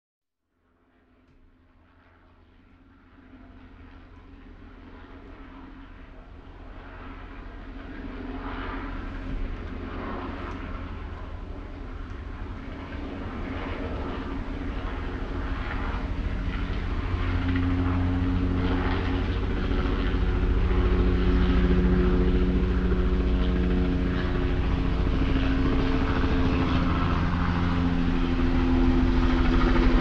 Psicadélia exacerbada, sem pretensões.